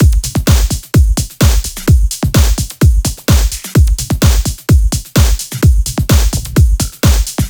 VFH1 128BPM Northwood Kit 1.wav